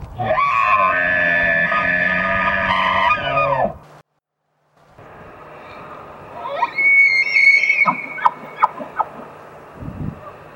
The high-pitched rutting call of North American Red deer (Cervus canadensis), aka the wapiti or elk. This call often ends with a "jerking cough" and is typically called a "bugle".
wapiti-bugle.mp3